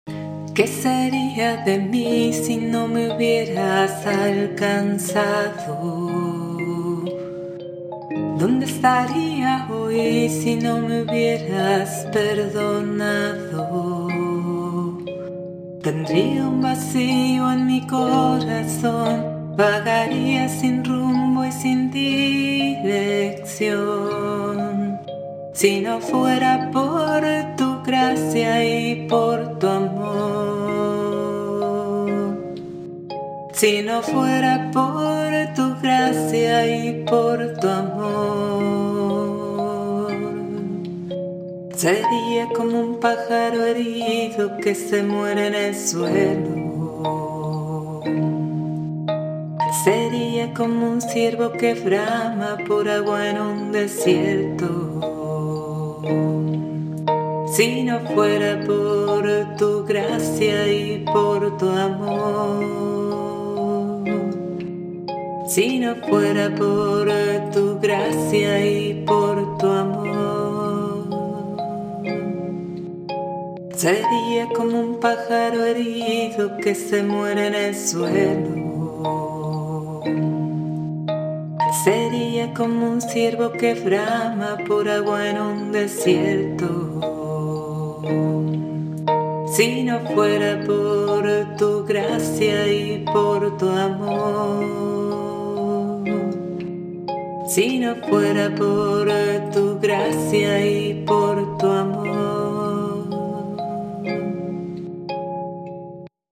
CANCION